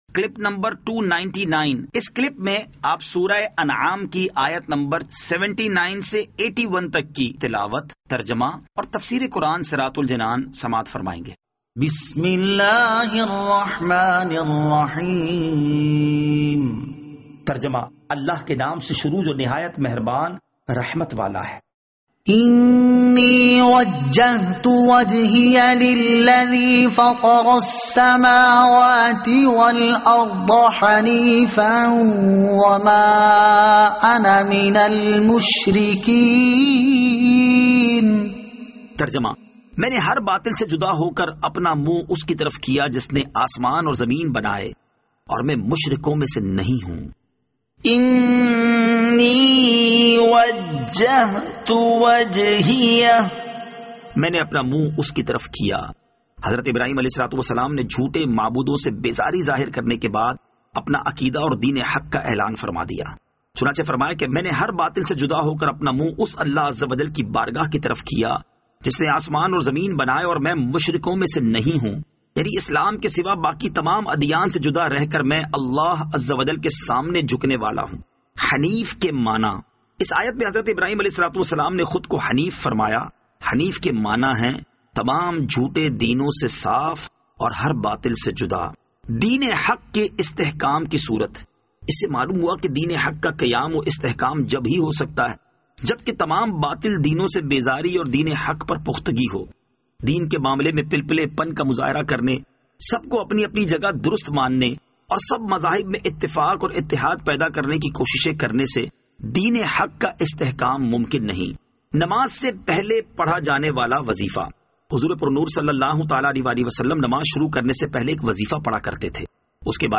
Surah Al-Anaam Ayat 79 To 81 Tilawat , Tarjama , Tafseer